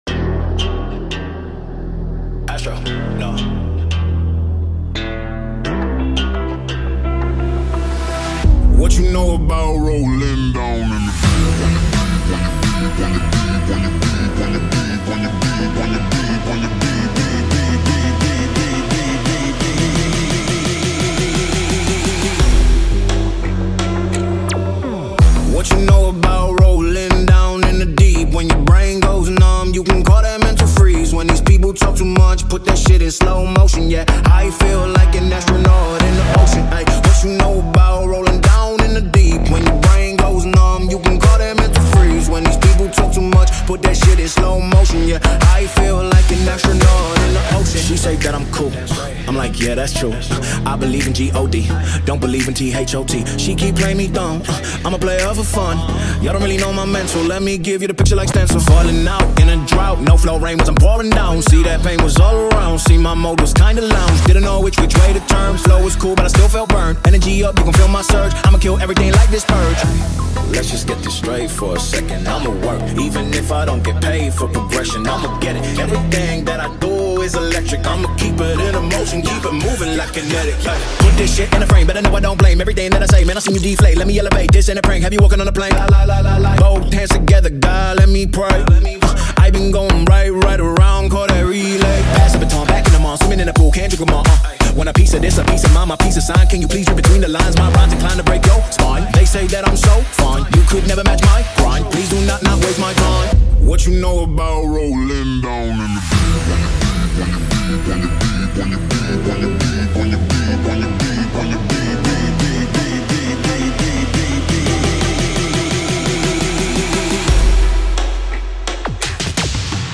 ریمیکس ترکیه ای